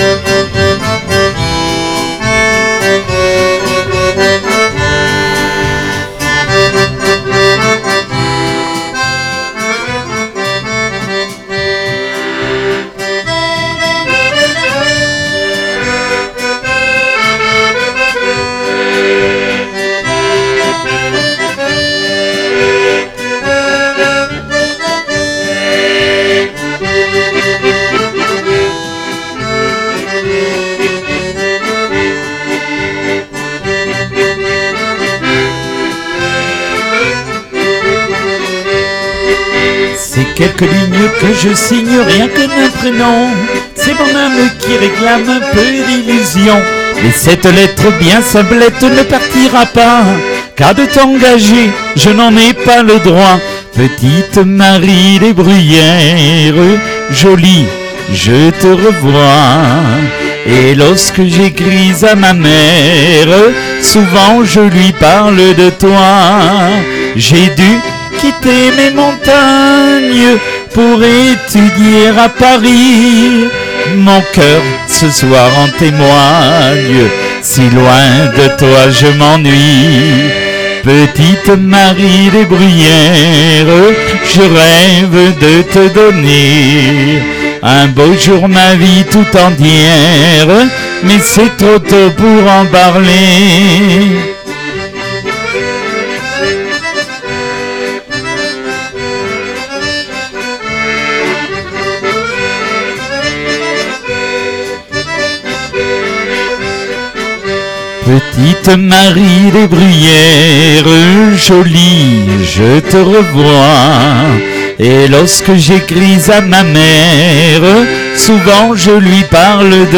EXTRAIT DE L’EMISSION D’ACCORDEON DU 0406